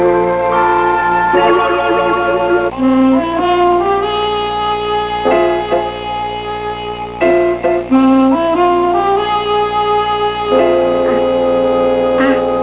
Well here are my (low quality) sounds.